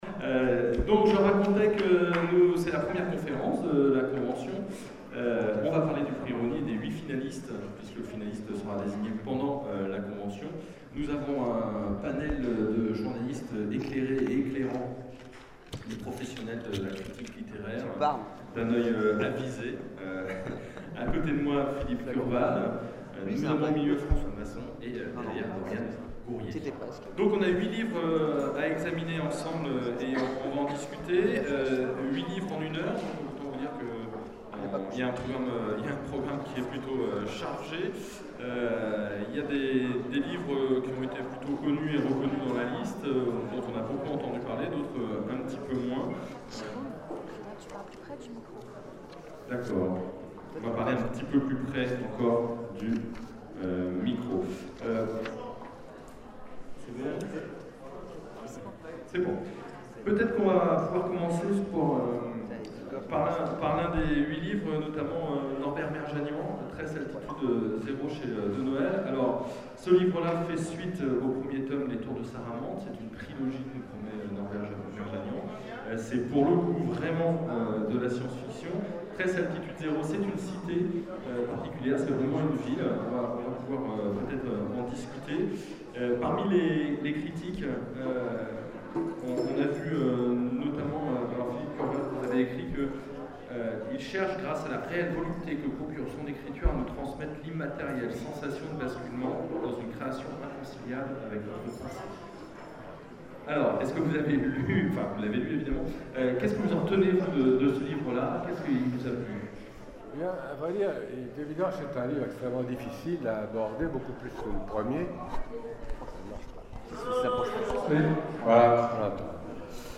Convention 2012 : Conférence sur le prix Rosny